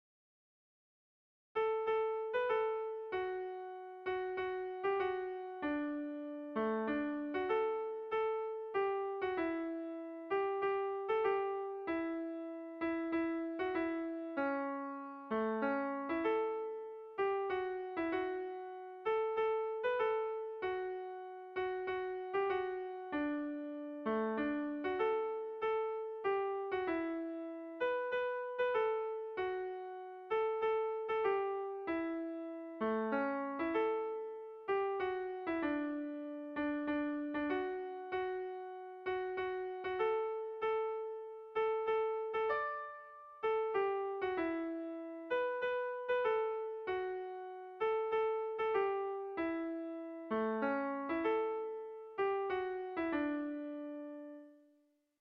Erlijiozkoa
Hamabiko handia (hg) / Sei puntuko handia (ip)
A1BA2A3DA3